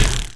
arrow_hit1.wav